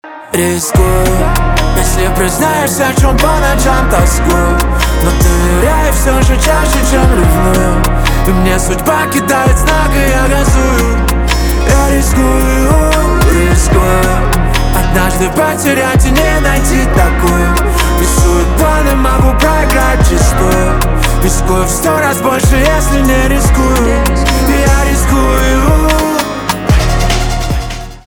поп
битовые , басы , гитара
чувственные